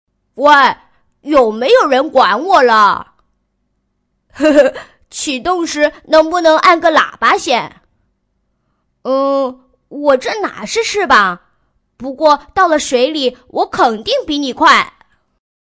【女90号童音】国语男童1
【女90号童音】国语男童1.mp3